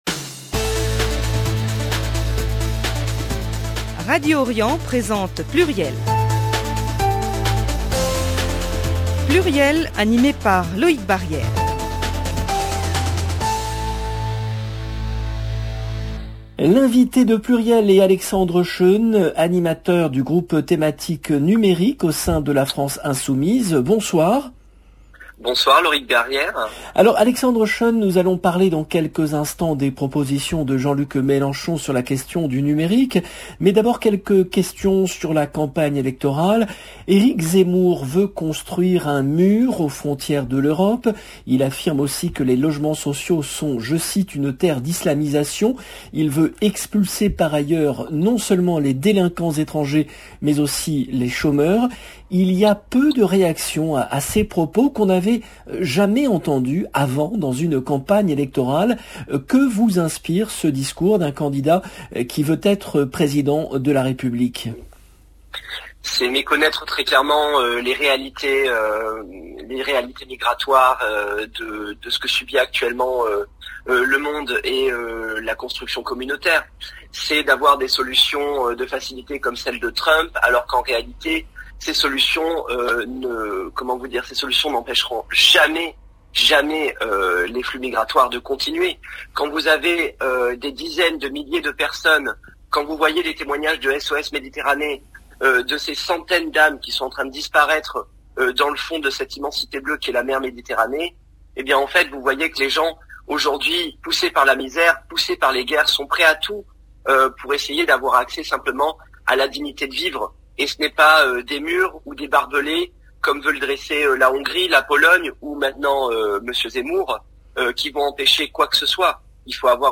Emission